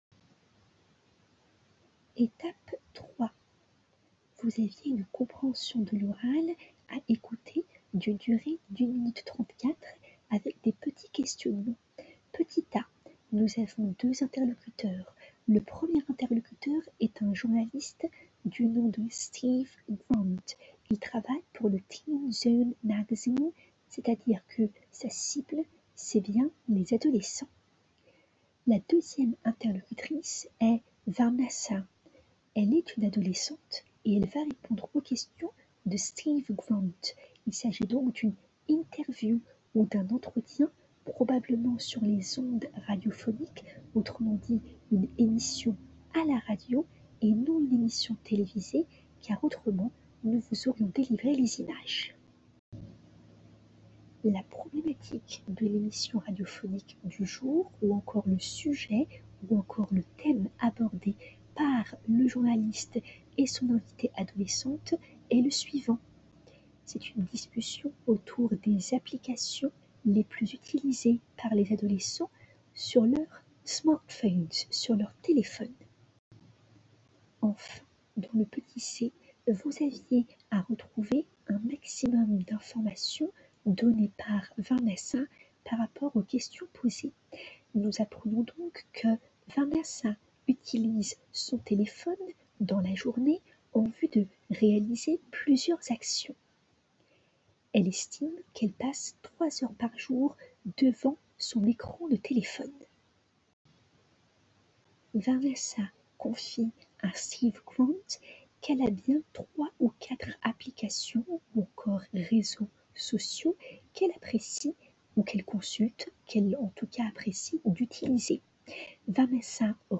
Je vous souhaite une très bonne écoute des pistes audio ci-dessous mettant en avant les explications orales du professeur relativement à la leçon à laquelle vous aviez à réfléchir, en classe inversée, pour le jeudi 02 avril 2020.
Audio 3 du professeur, d'une durée de 03:26: